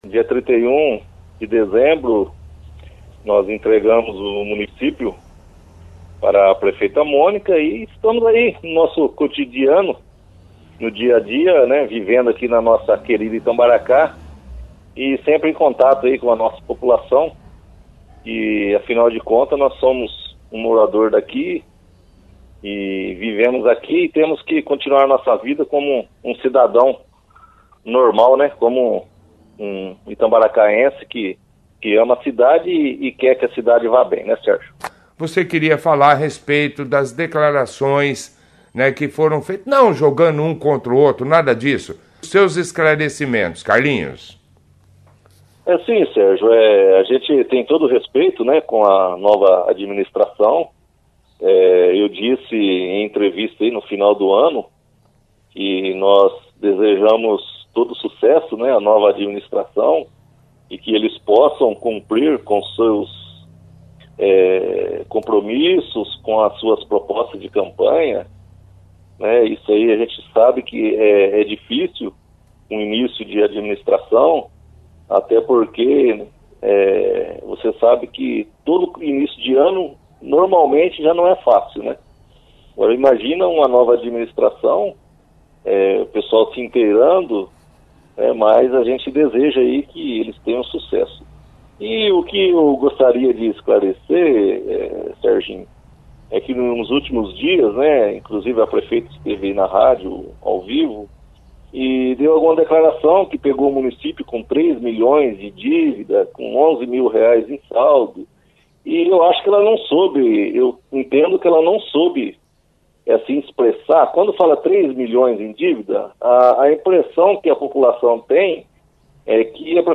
O ex-prefeito da cidade de Itambaracá Carlos Cesar de Carvalho o “Carlinhos” (foto), participou da 2ª edição do Jornal Operação Cidade, desta quarta-feira, 20/01, sobre as dividas segunda a nova administração deixada por ele no município.